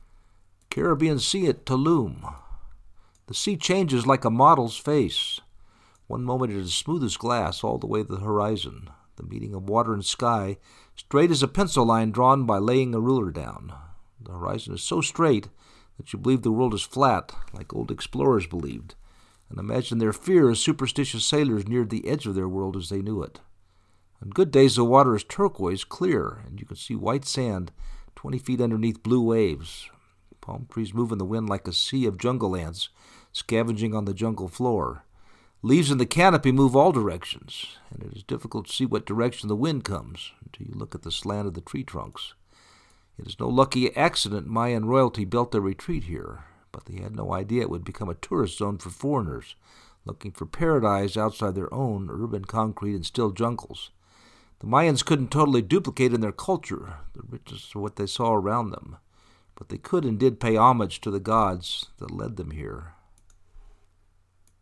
Caribbean Sea at Tulum, Mexico Hotel Zone - Tulum
caribbean-sea-at-tulum-2.mp3